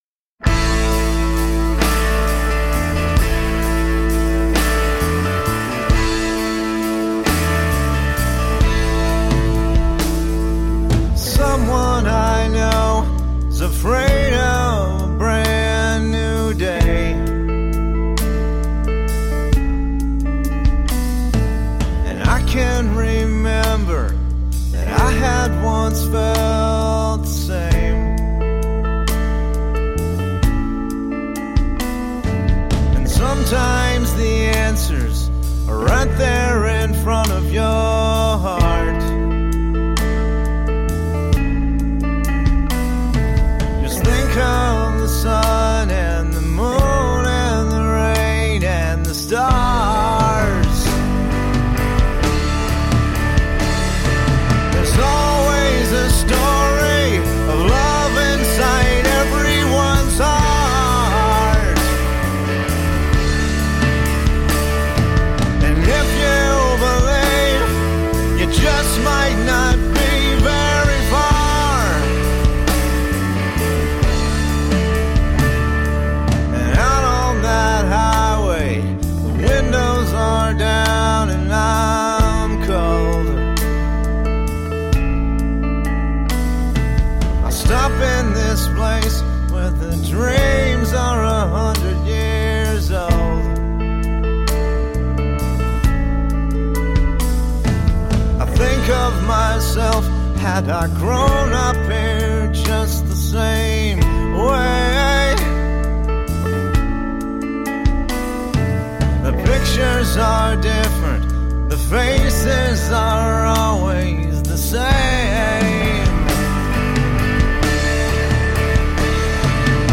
Indie rock when lyrics were king.
Tagged as: Alt Rock, Other